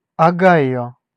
Ääntäminen
Ääntäminen GenAm: IPA : /oʊ.ˈhaɪ.oʊ/ US : IPA : [oʊ.ˈhaɪ.oʊ] Haettu sana löytyi näillä lähdekielillä: englanti Käännös Ääninäyte Erisnimet 1. Огайо {m} (Ogaio) Määritelmät Erisnimet A Capital and largest city: Columbus .